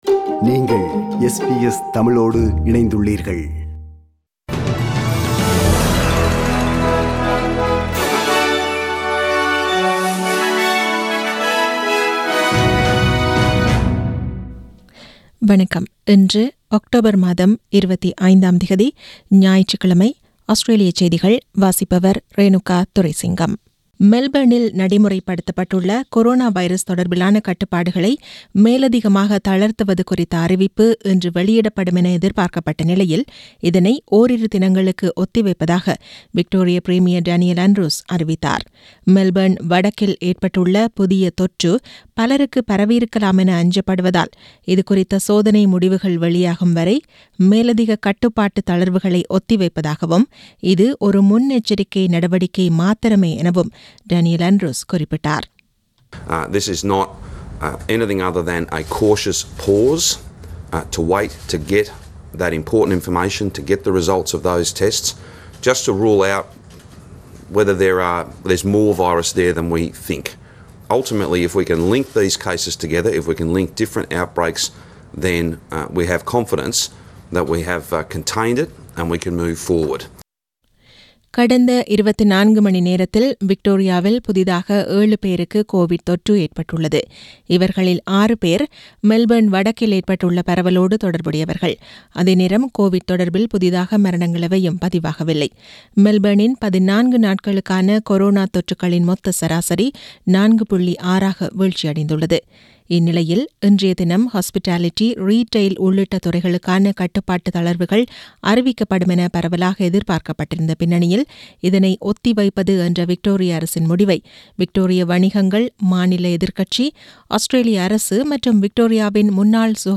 Australian news bulletin for Sunday 25 October 2020.